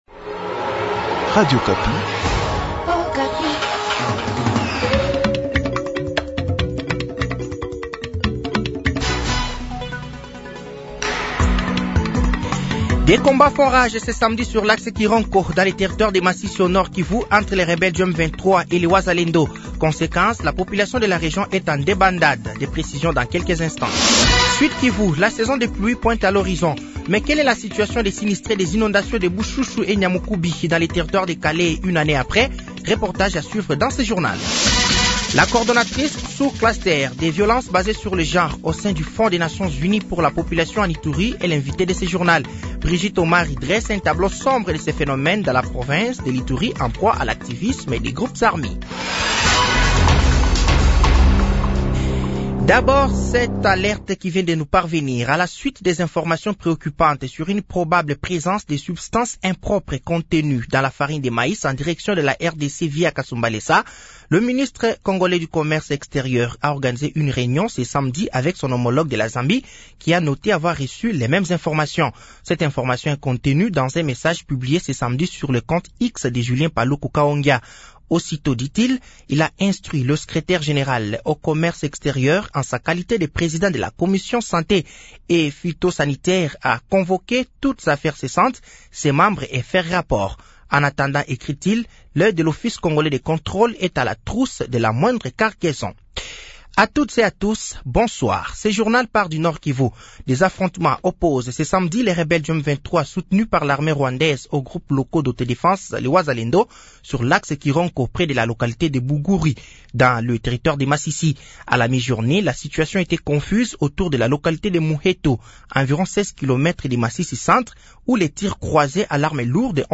Journal Soir
Journal français de 18h de ce samedi 24 août 2024